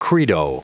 Prononciation du mot credo en anglais (fichier audio)
Prononciation du mot : credo